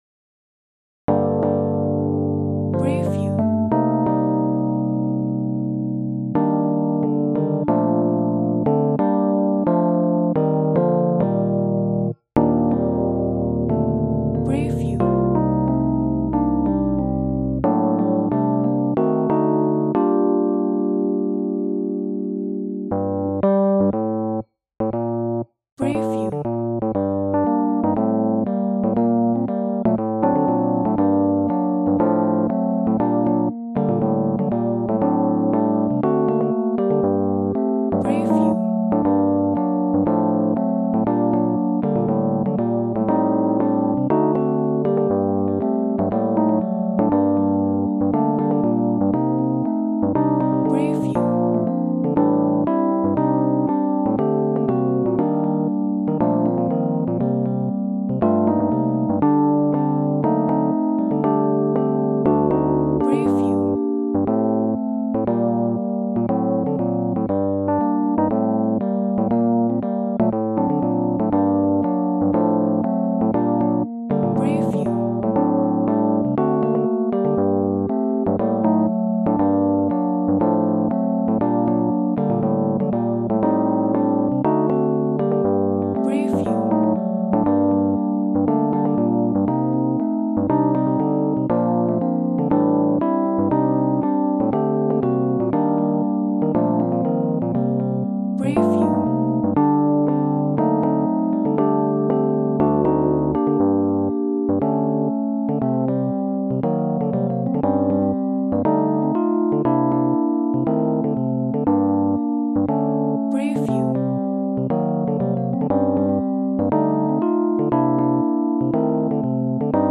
Вид: TTBB Жанр: спірічуел, госпел К-сть сторінок